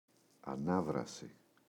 ανάβραση, η [aꞋnavrasi]